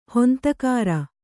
♪ hontakāra